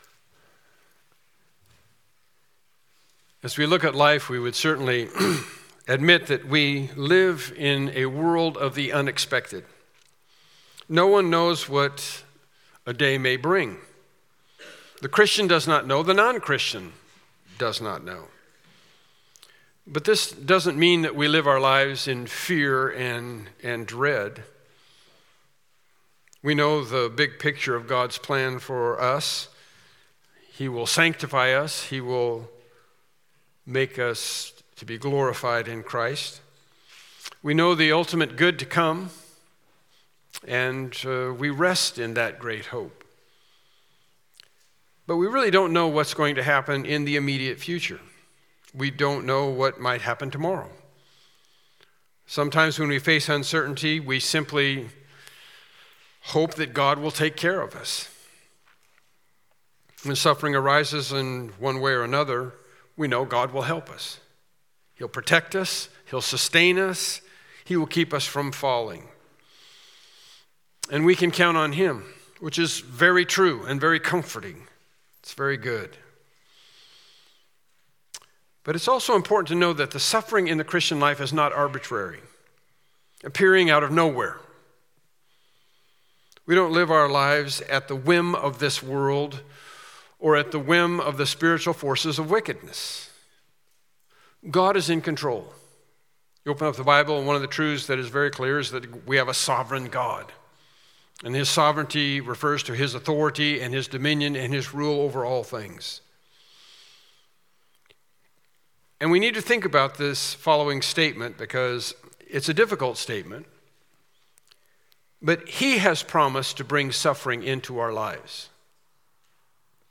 1 Peter 4:17-19 Service Type: Morning Worship Service Topics: Suffering